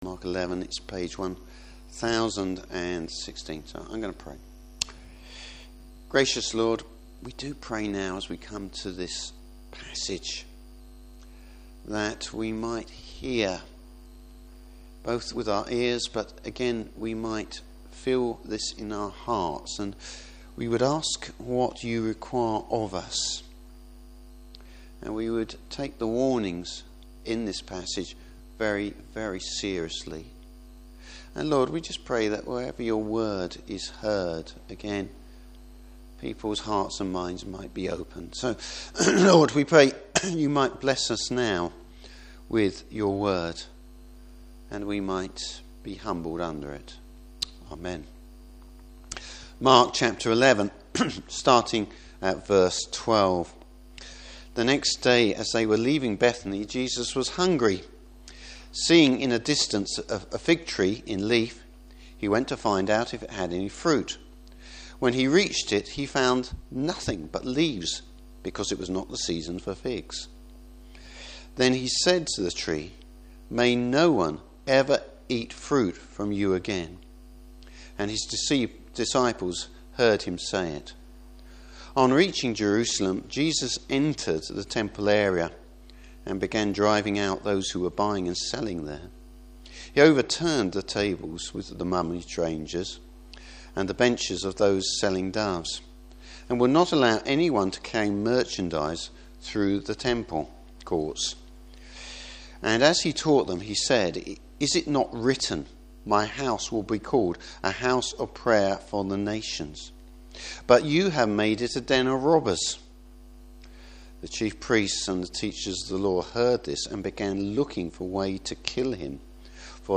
Service Type: Morning Service The righteous anger of the Lord!